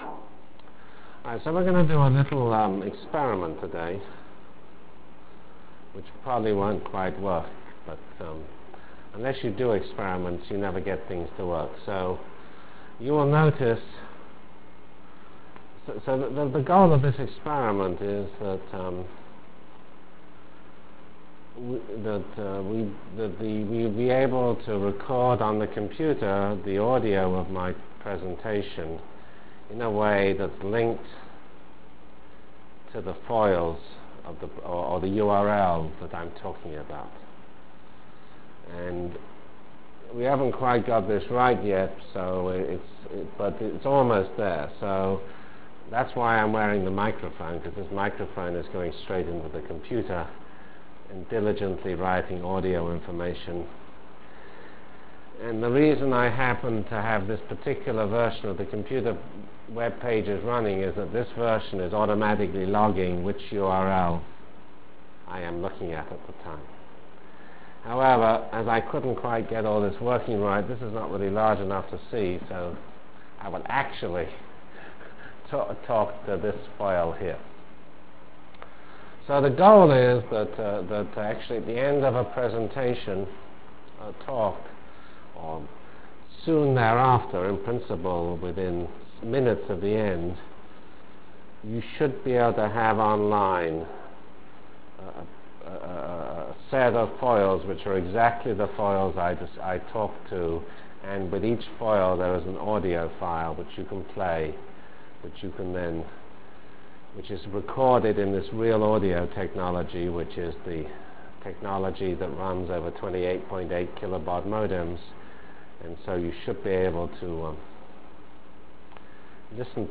Lecture of September 5 - 1996